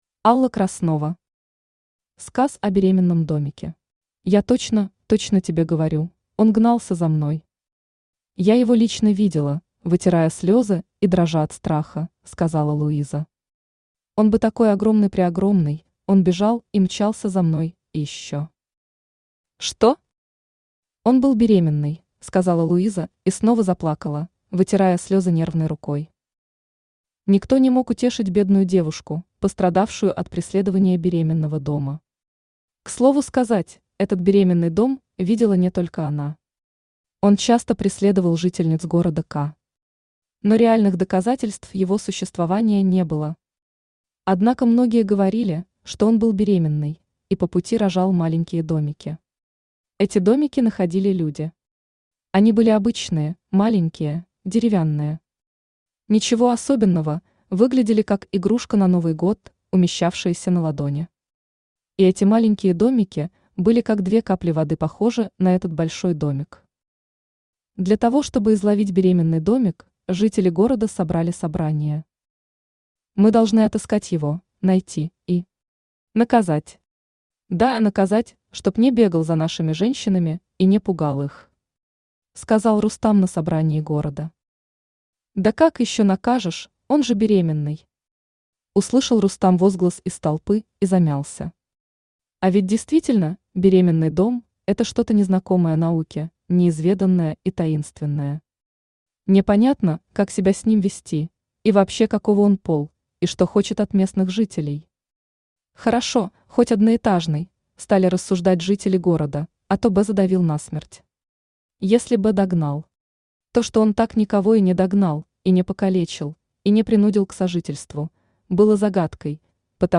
Аудиокнига Сказ о беременном домике